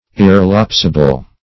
Search Result for " irrelapsable" : The Collaborative International Dictionary of English v.0.48: Irrelapsable \Ir`re*laps"a*ble\, a. Not liable to relapse; secure.
irrelapsable.mp3